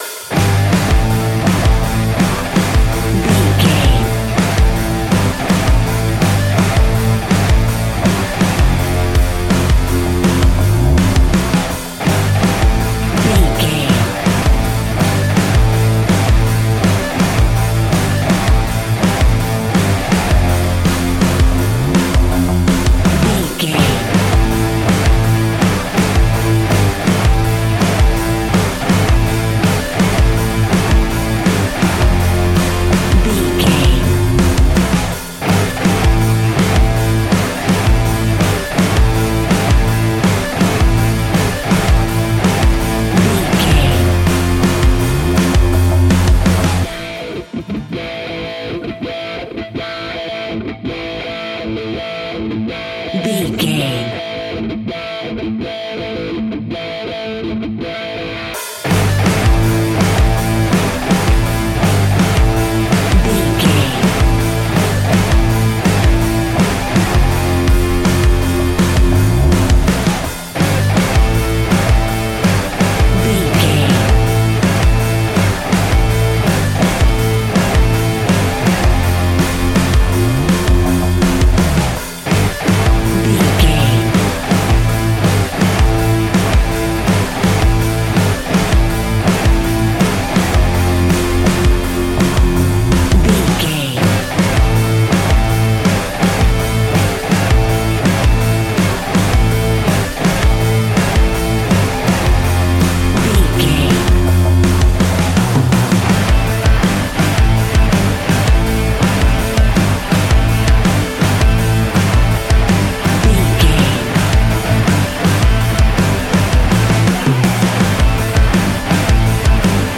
Ionian/Major
heavy rock
guitars
heavy metal
instrumentals